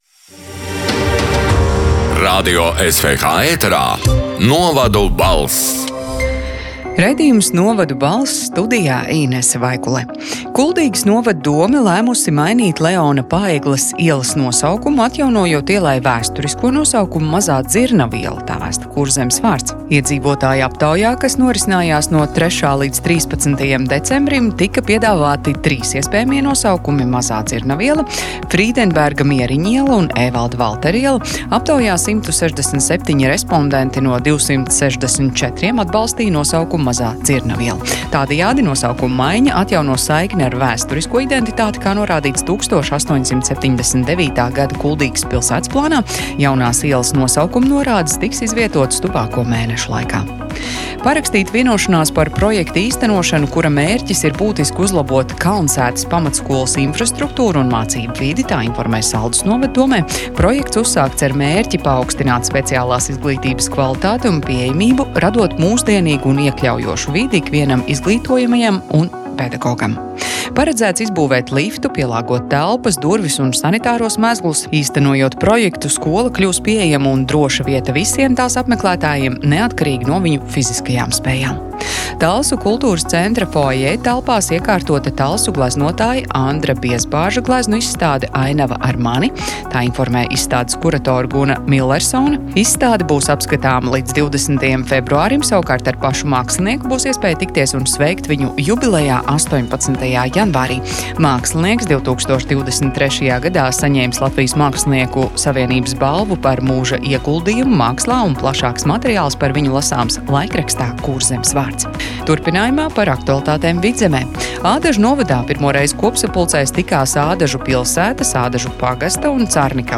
“Novadu balss” 15. janvāra ziņu raidījuma ieraksts: